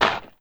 SPADE_Dig_02_mono.wav